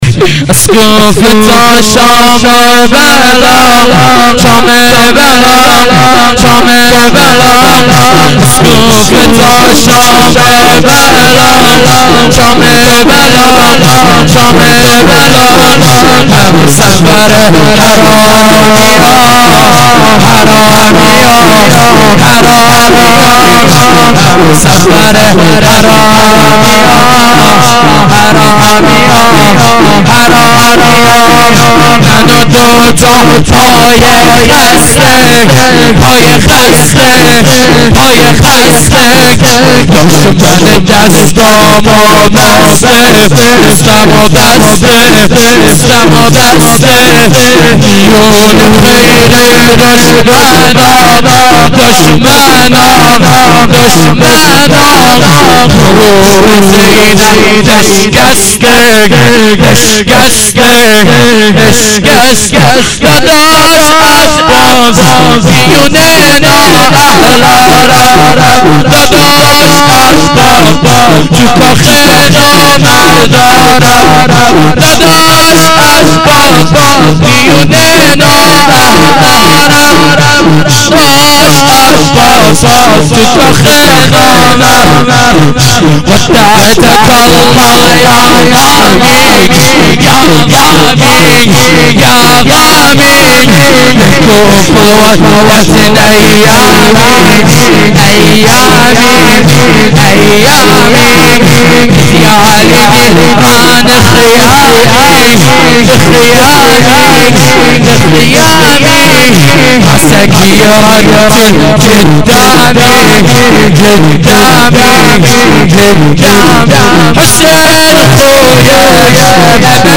شور عربی
fatemieh-aval-92-shab2-shor-farsi-arabi.mp3